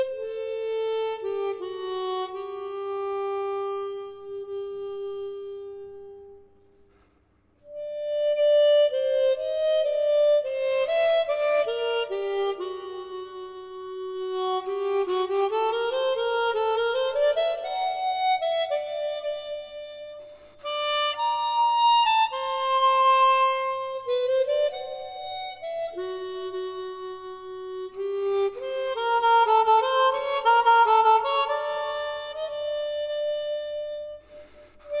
# Classical Crossover